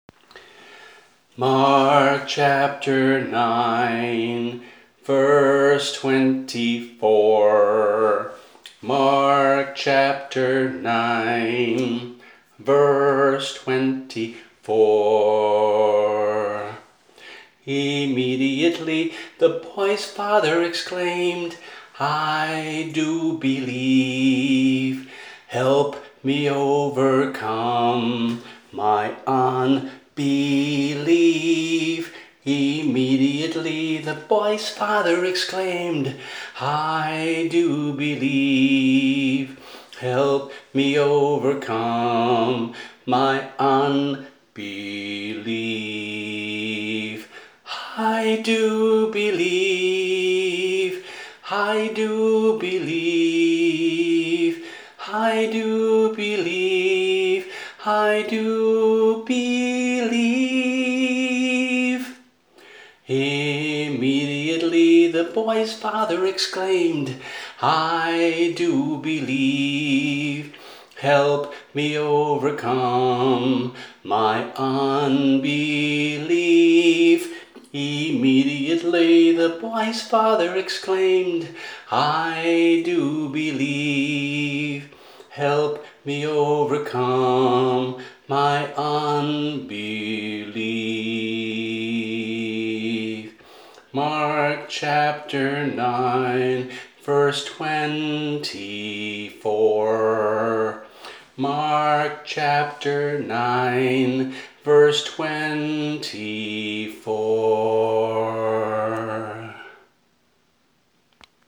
[MP3 - voice only]